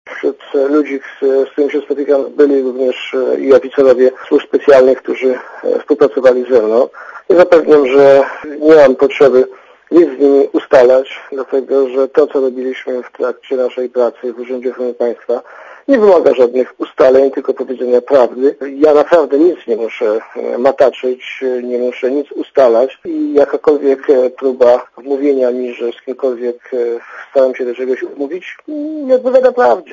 Posłuchaj komentarza Zbigniewa Siemiątkowskiego